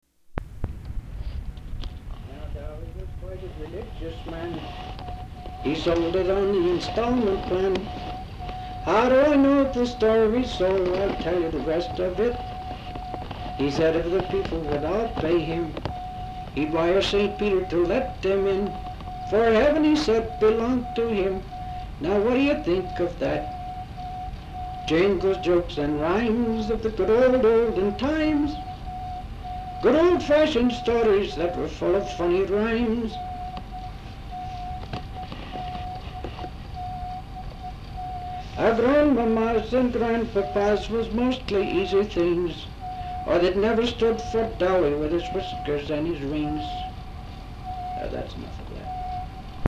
Folk songs, English--Vermont
sound tape reel (analog)
Location Wilmington, Vermont